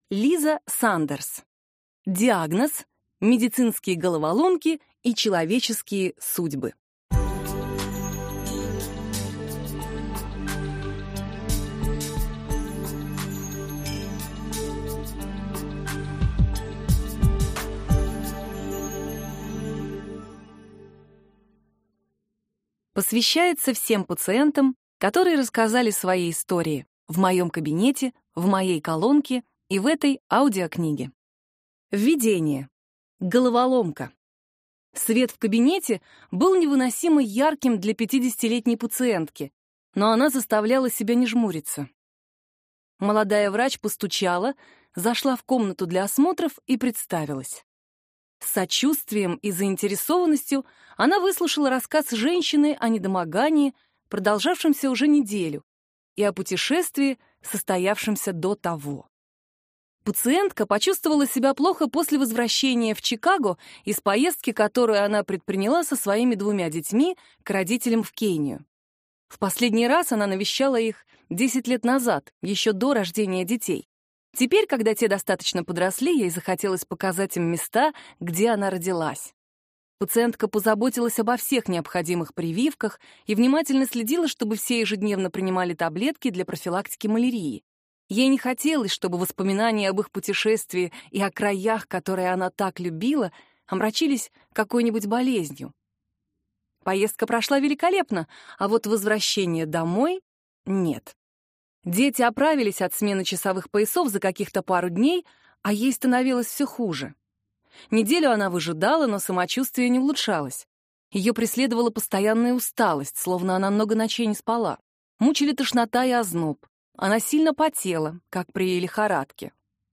Аудиокнига Диагноз. Медицинские головоломки и человеческие судьбы | Библиотека аудиокниг